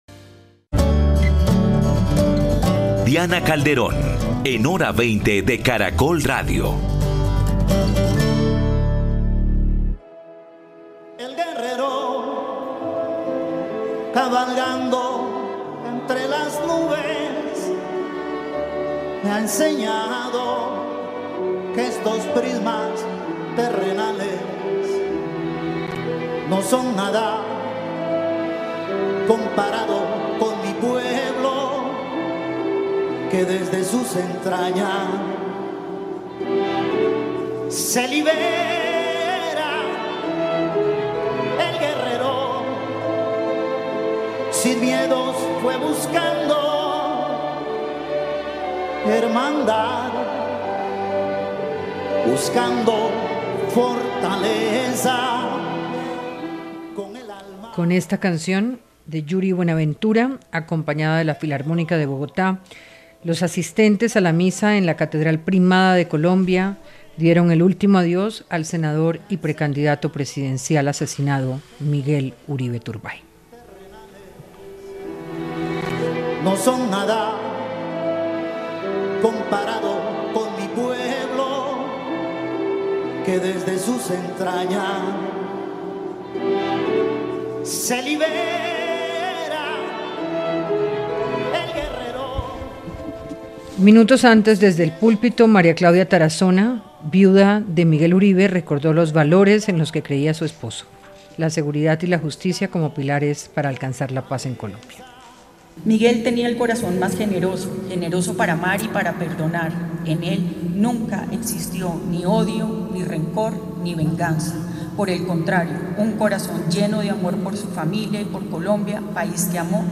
Una mirada a la cooperación en el territorio fronterizo de 2.341 kilómetros, la soberanía nacional, seguridad, paz y violencia por la presencia de grupos armados en el contexto de la propuesta de ‘zona binacional’ colombo-venezolana. Lo que dicen los panelistas